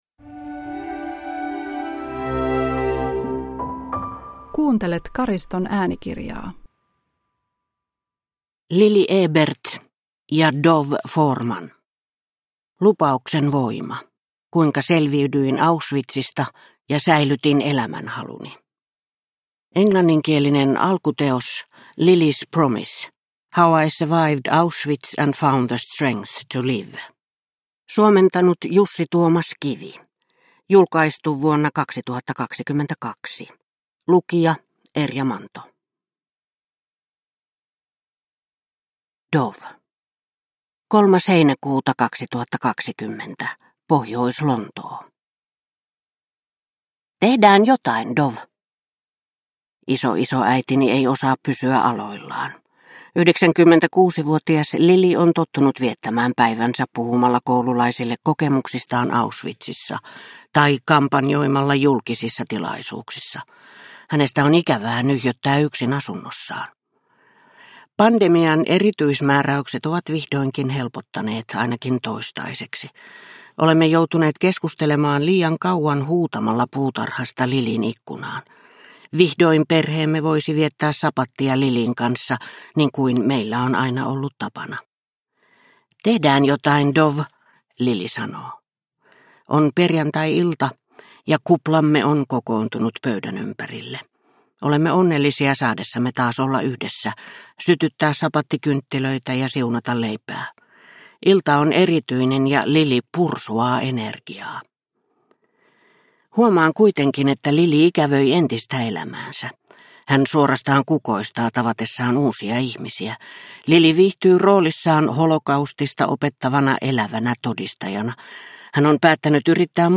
Lupauksen voima – Ljudbok – Laddas ner